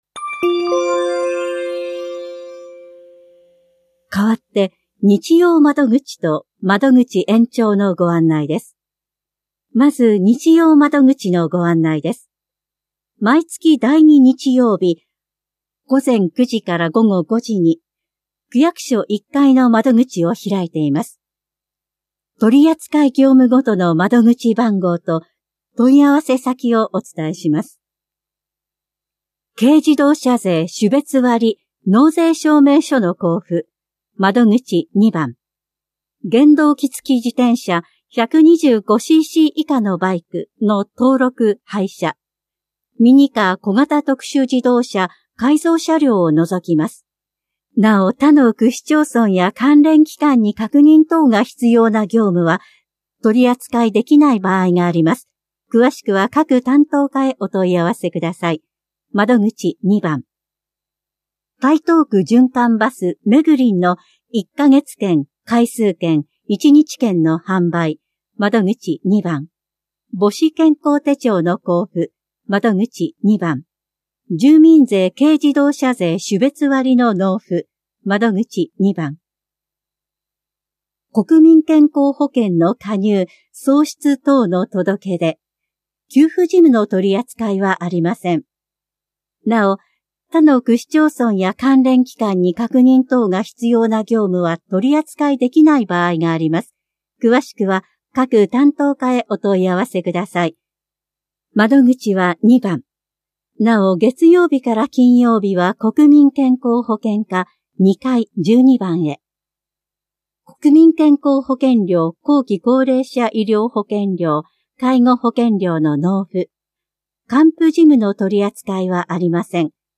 広報「たいとう」令和6年3月20日号の音声読み上げデータです。